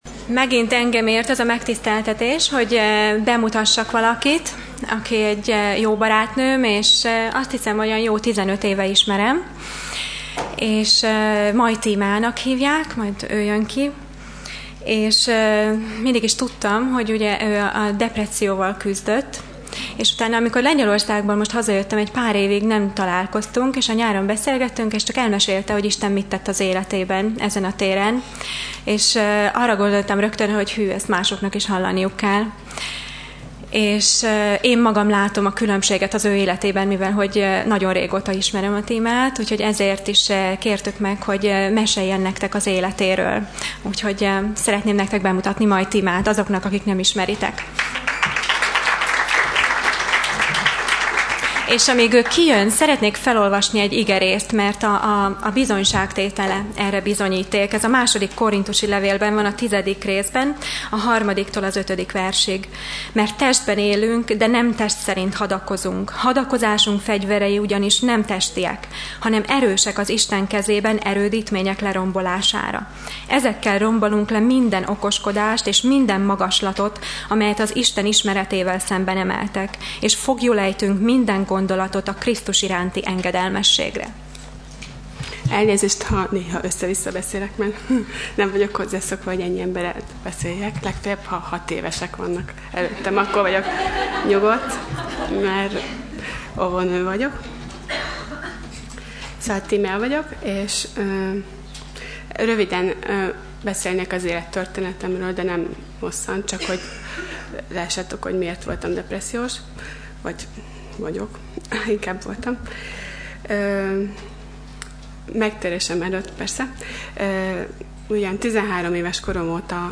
bizonyságtétel
Sorozat: Női Hétvége 2010 Alkalom: Női bibliaórák